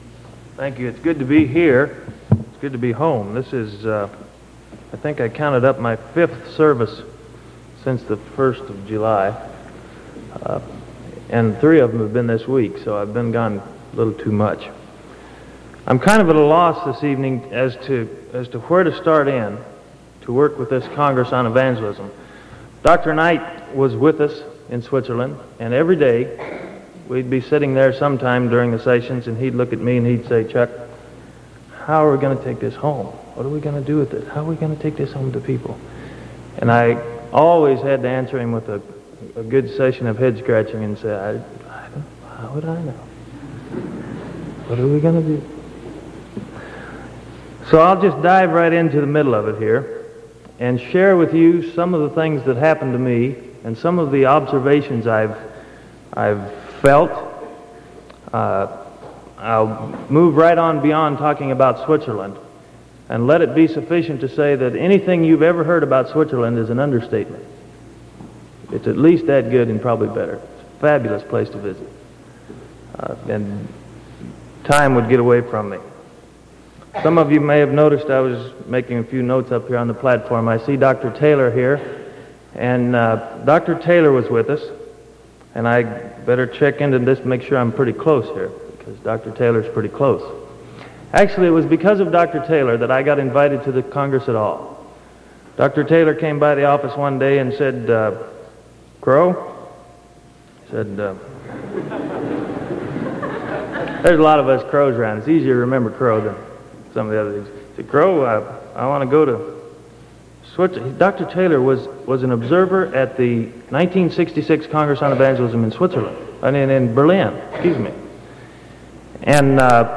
Sermon September 11th 1974 PM